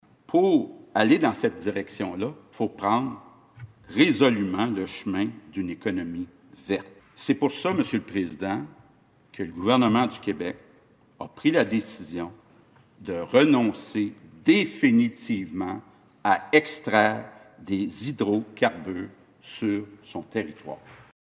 C’est ce qui ressort du discours inaugural du premier ministre François Legault prononcé hier à l’Assemblée nationale.